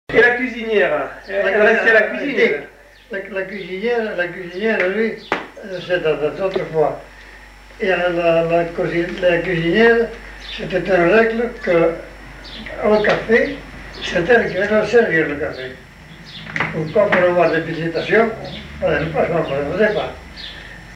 Genre : témoignage thématique